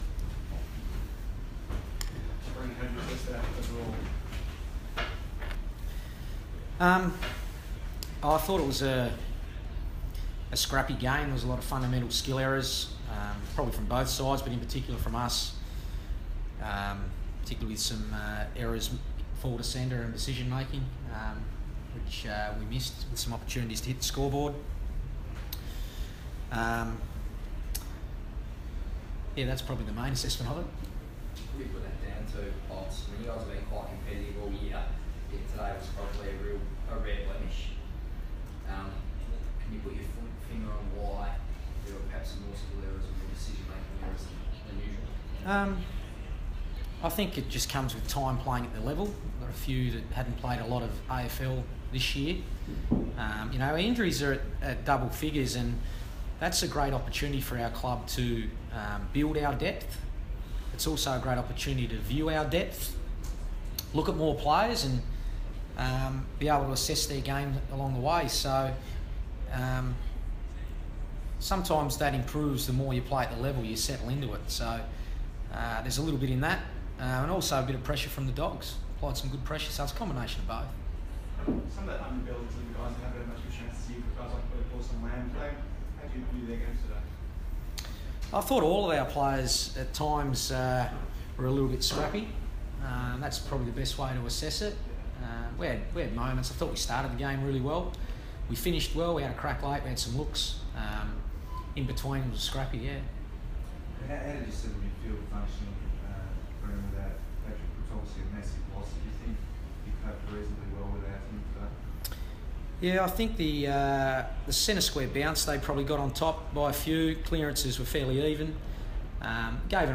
Carlton coach Brendon Bolton fronts the media after the Blues' 20-point loss to the Bulldogs.